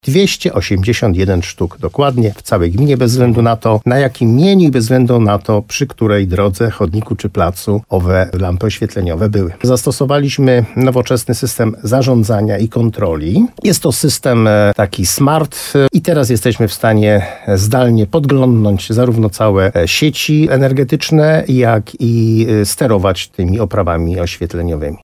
O liczbie i technicznych nowościach wymienionych lamp mówi wójt gminy Moszczenica Jerzy Wałęga.